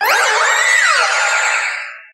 Grito de Mega-Diancie.ogg
Grito_de_Mega-Diancie.ogg